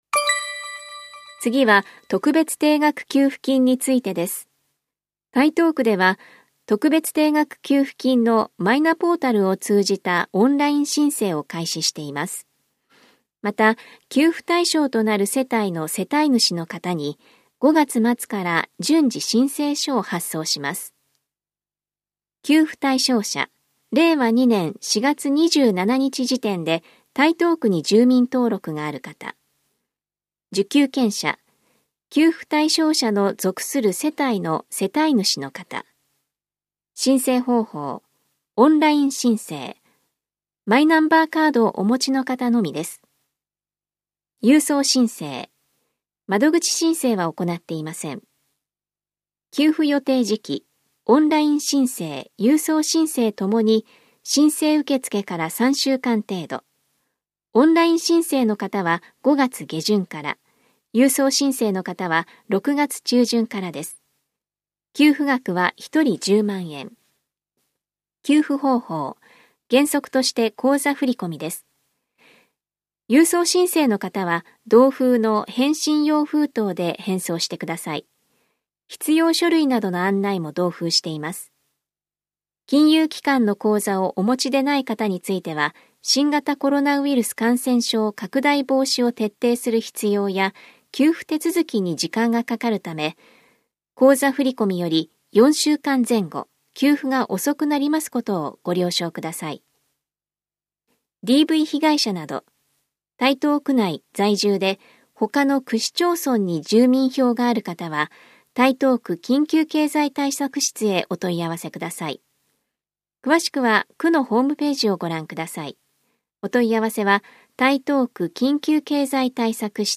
広報「たいとう」令和2年5月20日号の音声読み上げデータです。